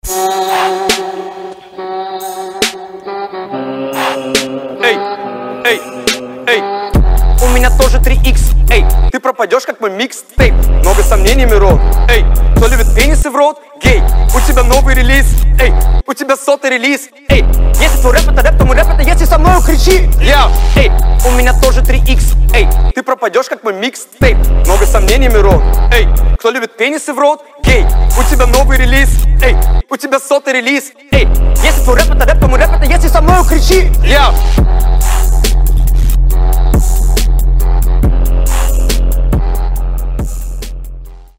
• Качество: 320, Stereo
громкие
Хип-хоп
Bass
Версус Батл